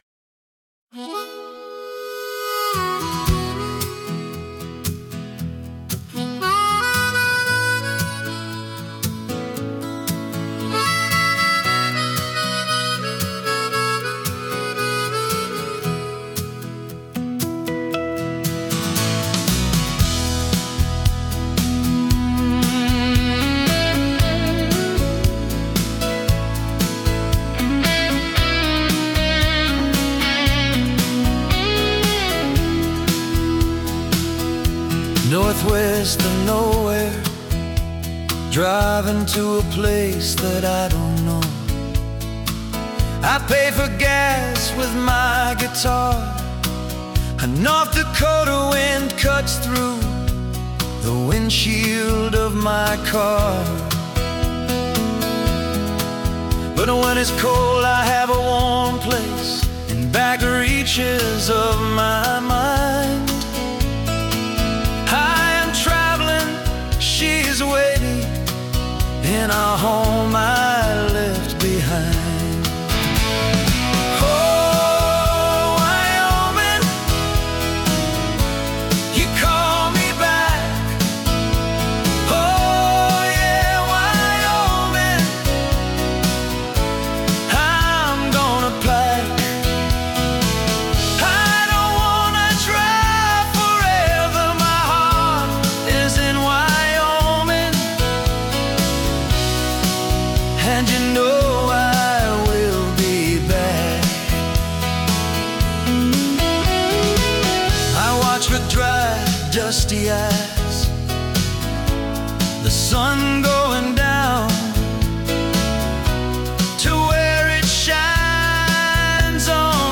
I was trying to evoke the sparse open wilderness.